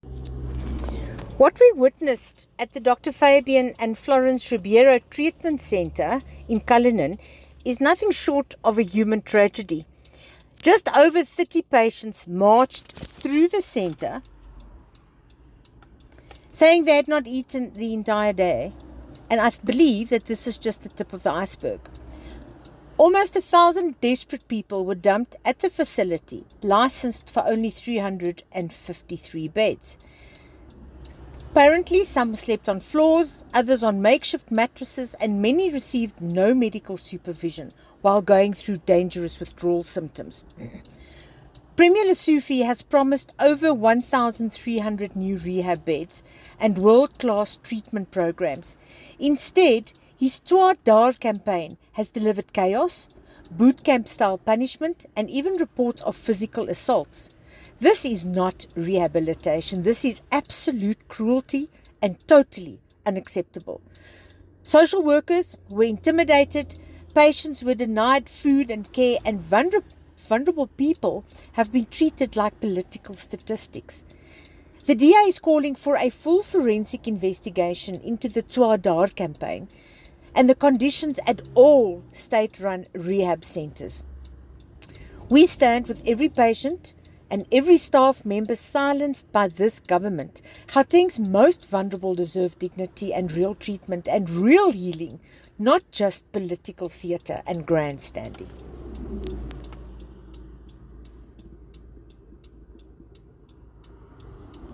Afrikaans soundbites by Bronwynn Englebrecht MPL.